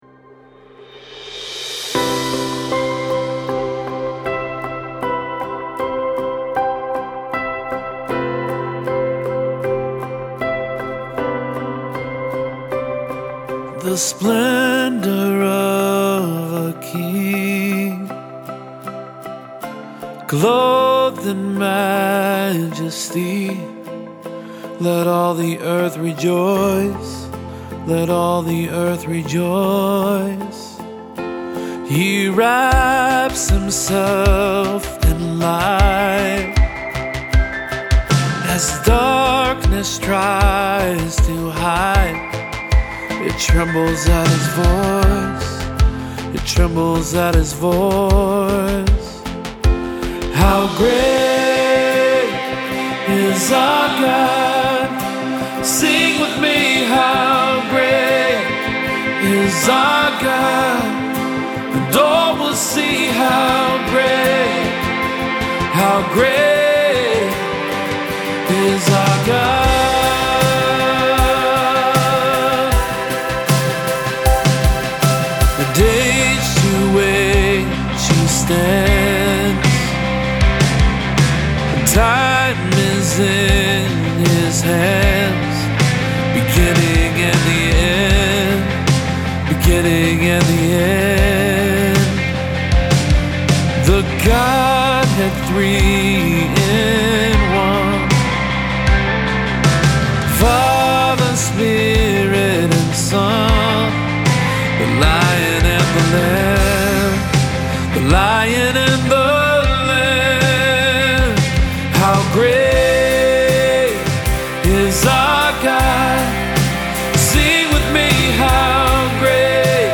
Full arrangement demo
• Keys: C, Bb, and A
• Tempo: 78 bpm, 4/4 time
STYLE: RE-ARRANGEMENT
• Drums
• Acoustic Guitar
• Piano
• Electric Guitar (2 tracks)
• Bass
• Synths (3 tracks)
• Gang Vocals